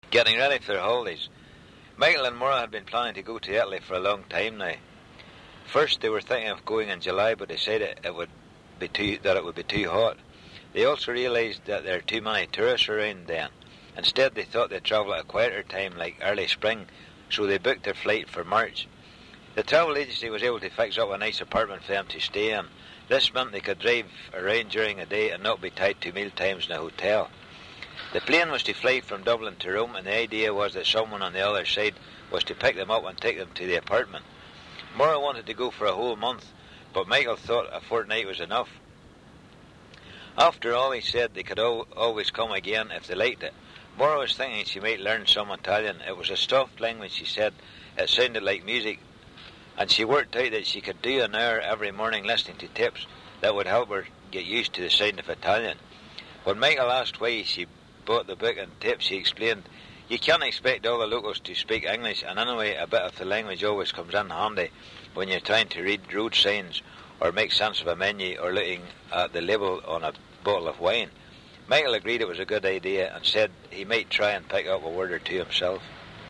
Northern Irish English
Northern Irish English is clearly recognisable by its intonation and by a variety of segmental features such as a retroflex [ɻ] and a high mid vowel [ʉ] in words like fool [fʉˑl]. Note that vowel length is not always distinctive, especially in Ulster Scots.
Ireland_North_Broad.wav